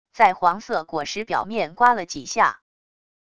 在黄色果实表面刮了几下wav音频